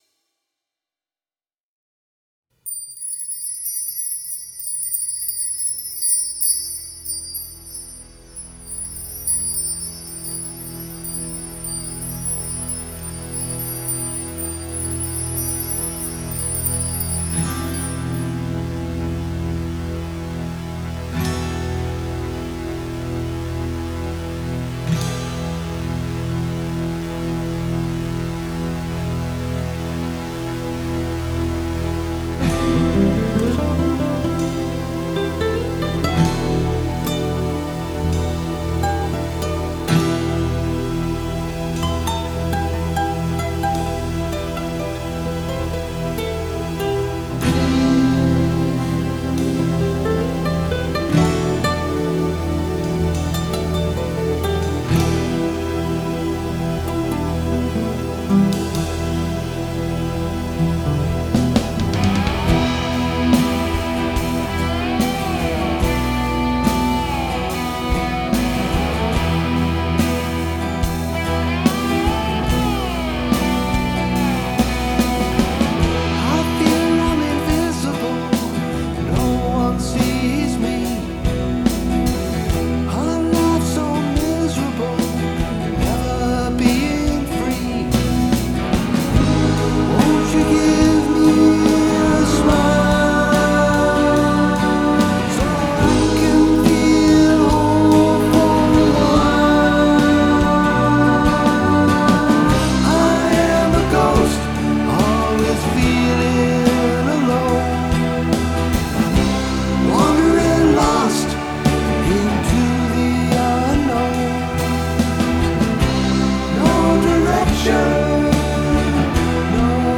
Ghost - dense Pink Floyd-type mix
I haven't posted in this section of the forum in ages, most of my mixes have been fairly easy ones, but when I get to a mix with a lot of tracks (this one has 40 including FX buses), I start doubting my ears.